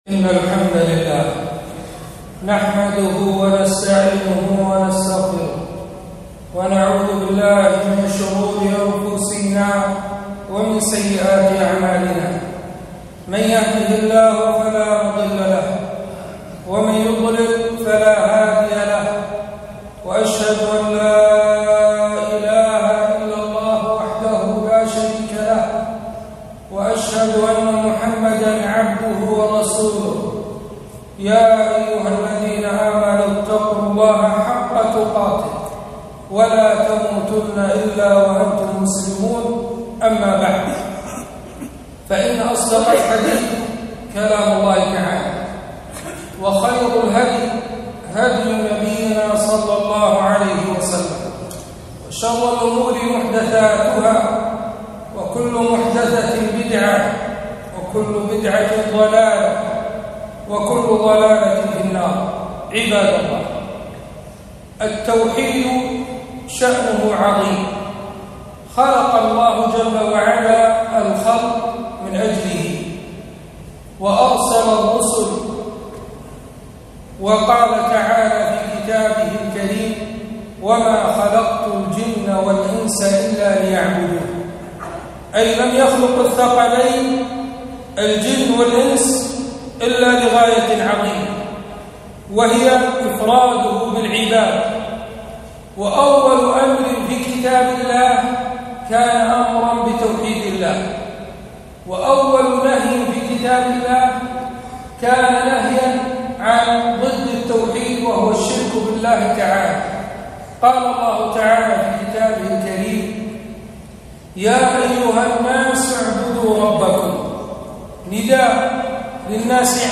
خطبة - فضل التوحيد وخطر الشرك